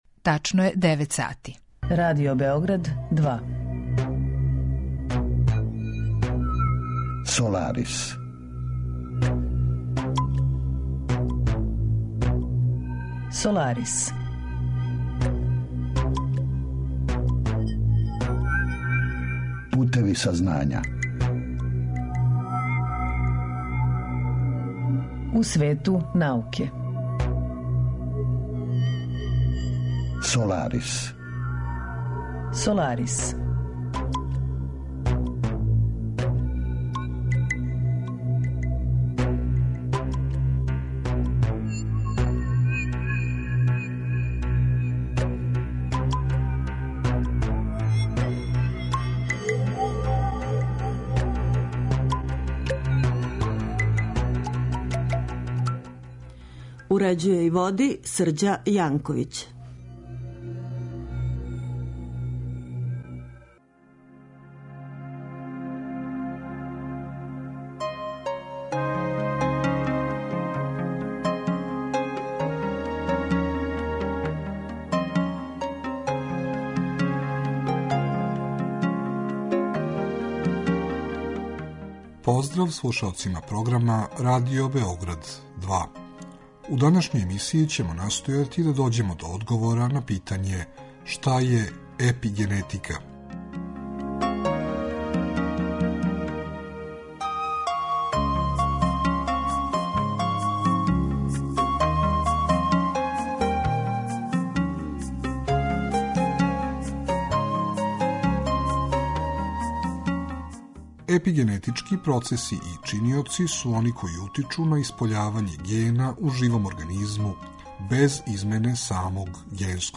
Разговор је први пут емитован 28. октобра 2015.